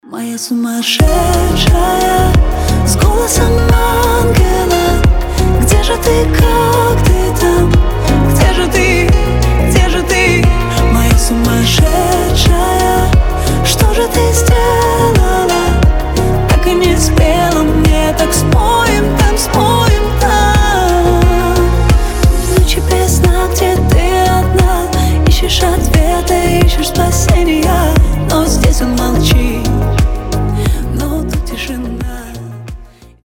• Качество: 320, Stereo
грустные
красивый женский голос